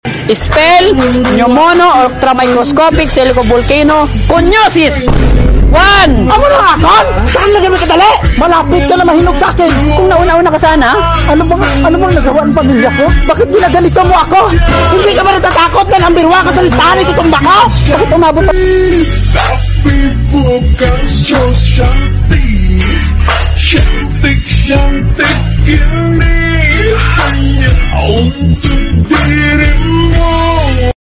hahaha . . . sound effects free download